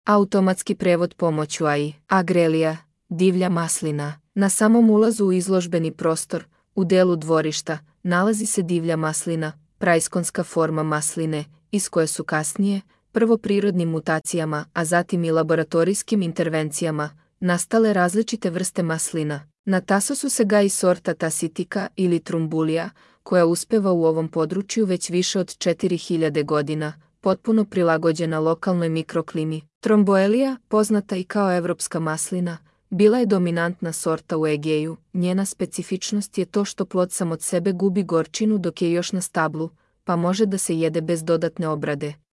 Аудио водич / Audio vodič.